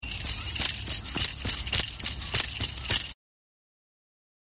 これがフットタンバリンだ。
ｼｬﾝｼｬﾝｼｬﾝｼｬﾝｼｬﾝｼｬﾝｼｬﾝｼｬﾝｼｬﾝｼｬﾝ